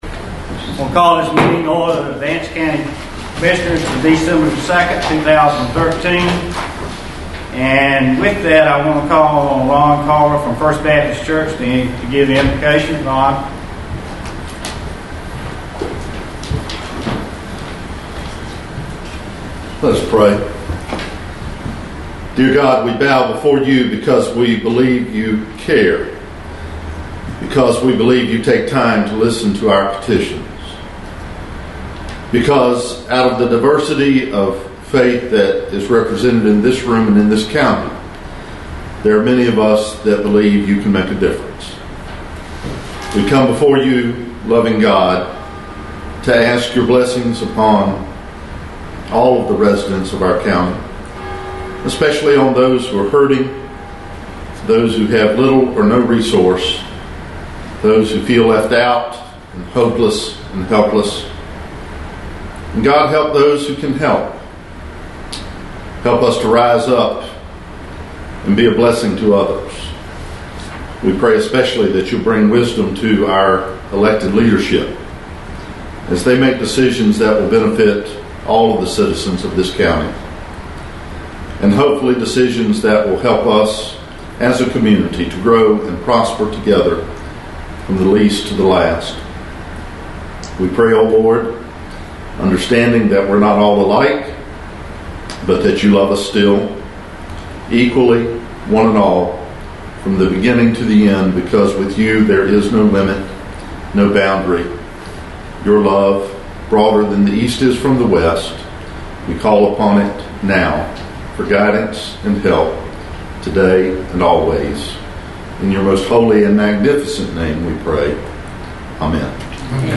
Audio – Vance County Board of Commissioners Meeting December 2nd, 2013